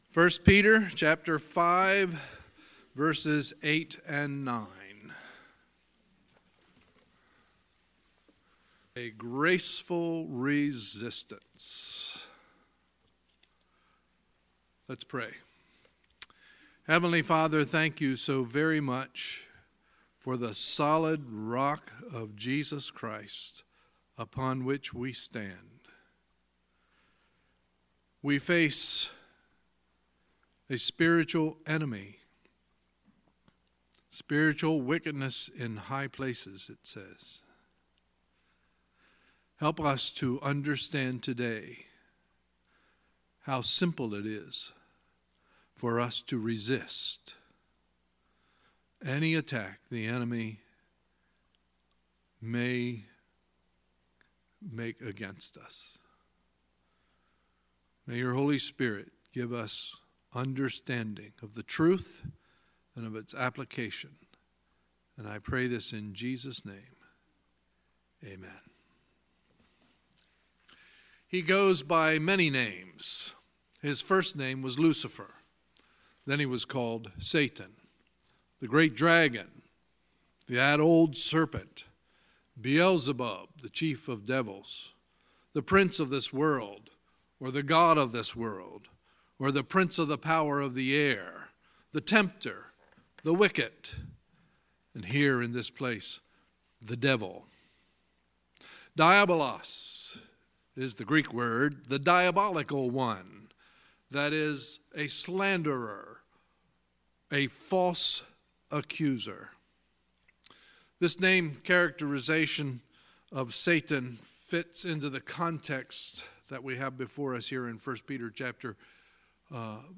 Sunday, August 12, 2018 – Sunday Morning Service